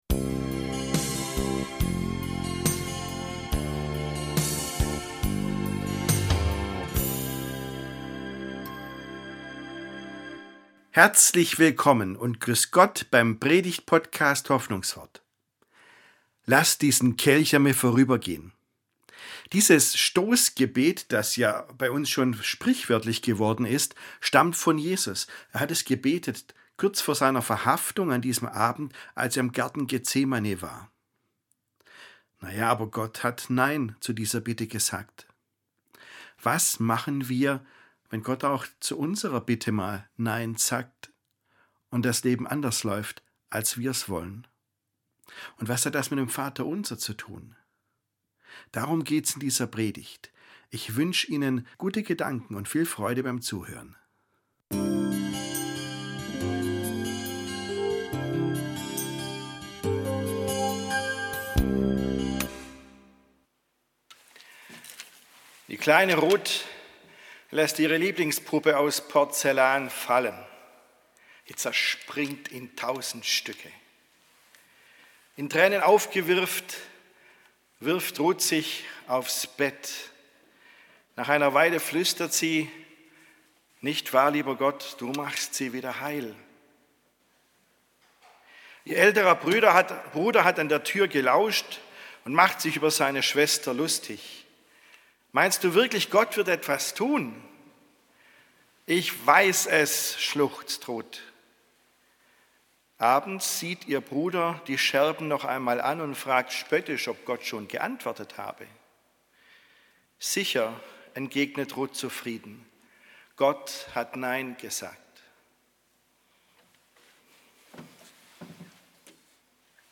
Wenn Gott Nein sagt ~ Hoffnungswort - Predigten